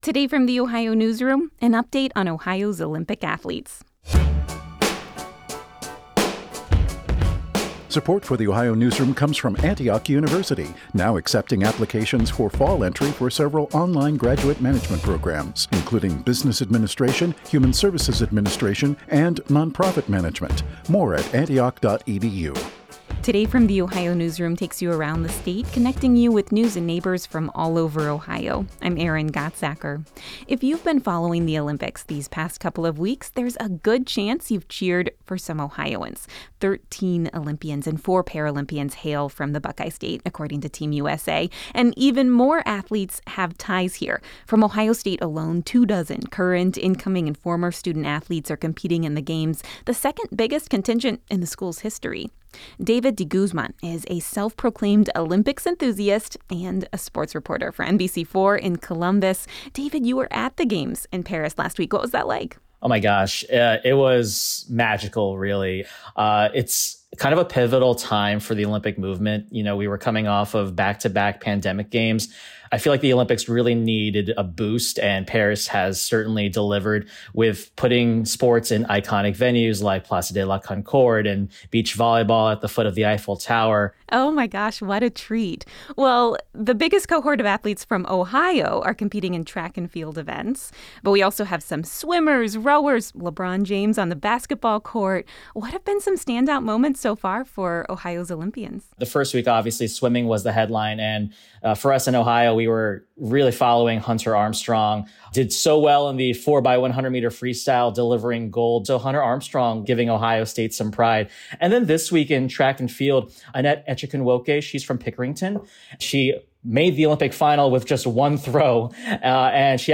This interview has been lightly edited for brevity and clarity.